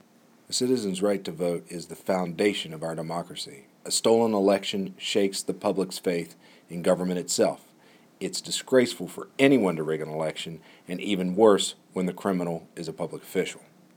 Click here to listen to an audio clip from U.S. Attorney Goodwin regarding today’s sentencings